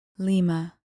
Pronounced: LEE-mah